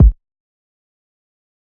Rack_Kick.wav